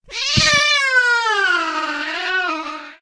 black_cat_dies.ogg